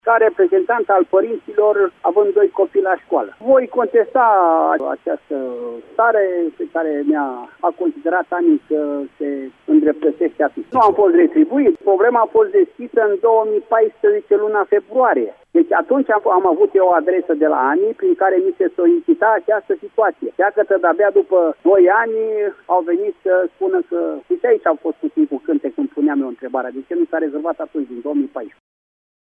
Ascultaţi declaraţia primarului Gheorghe Românu: